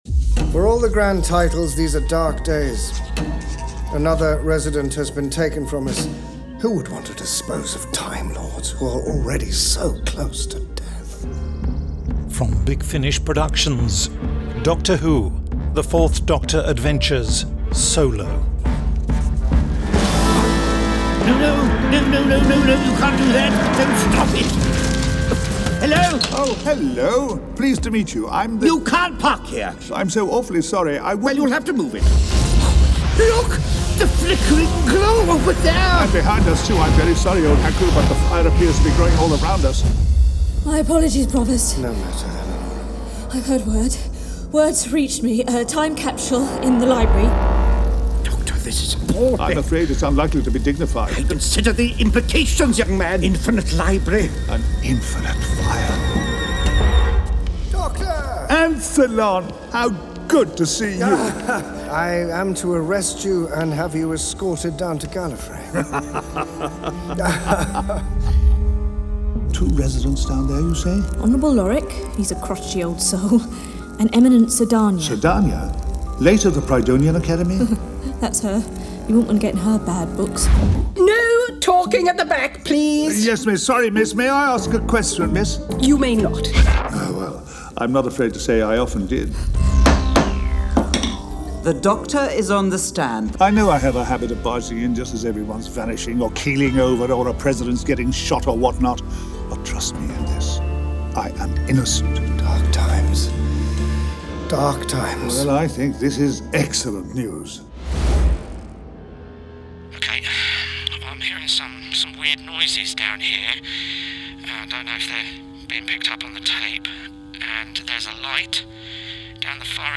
Starring Tom Baker